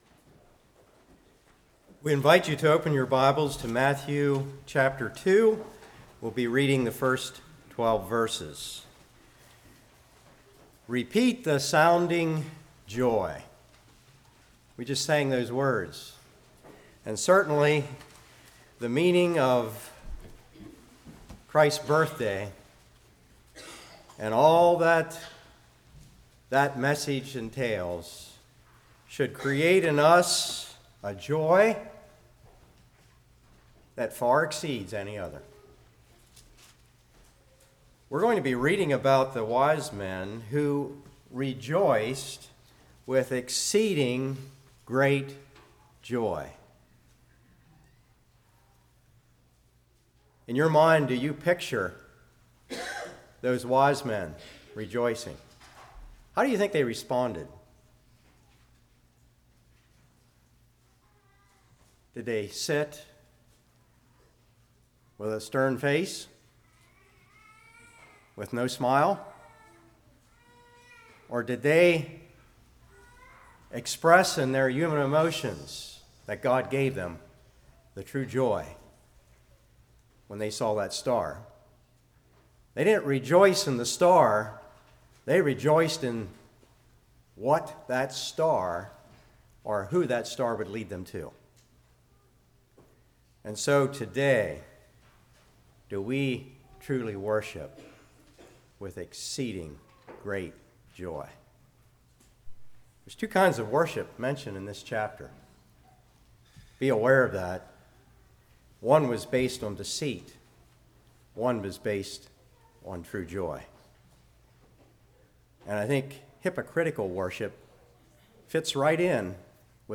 Matthew 2:1-12 Service Type: Morning Gold